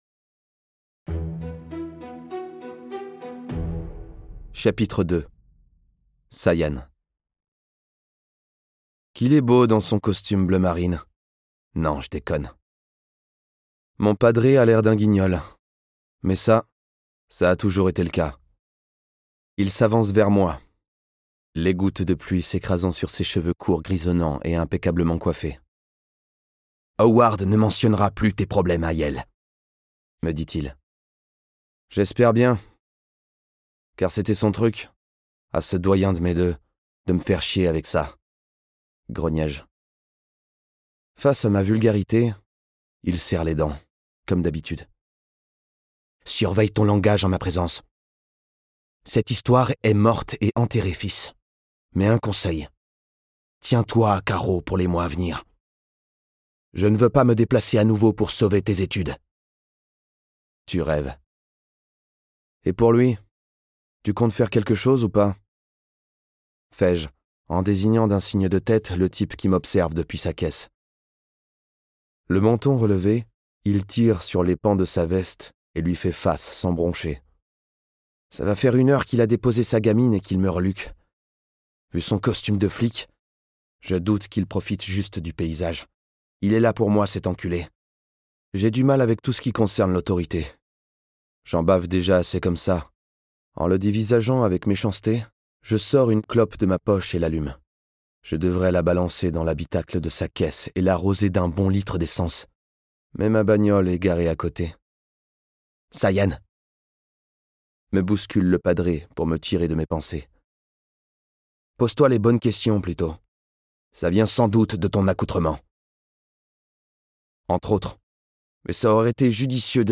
Ce livre audio est interprété par une voix humaine, dans le respect des engagements d'Hardigan.